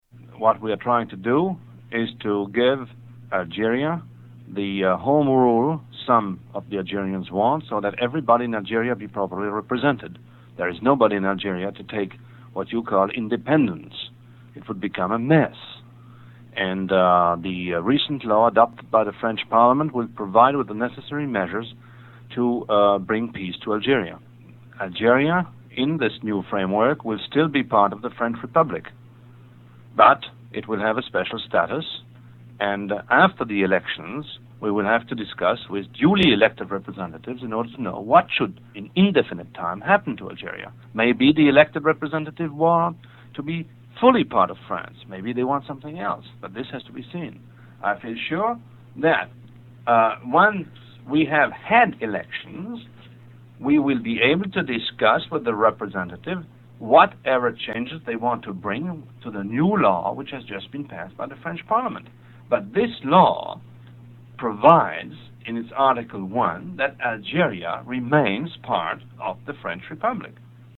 French Politician discusses Algerian War